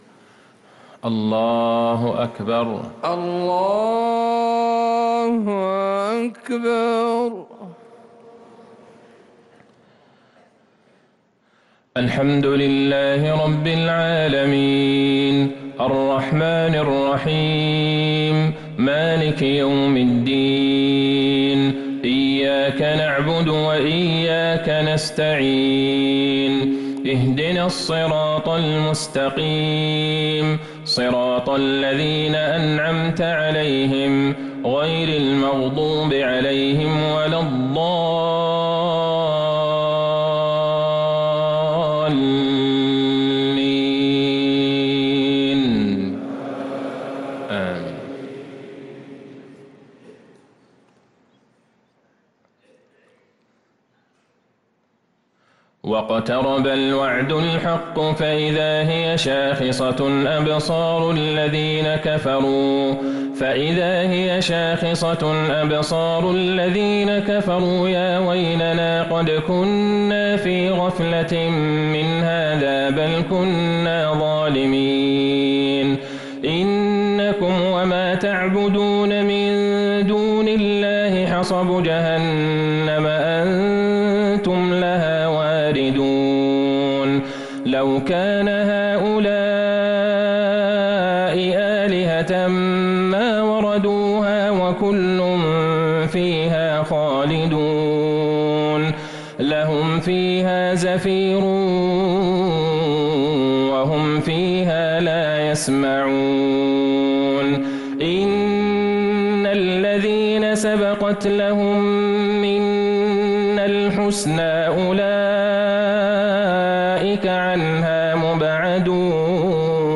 صلاة العشاء للقارئ عبدالله البعيجان 30 ربيع الآخر 1445 هـ
تِلَاوَات الْحَرَمَيْن .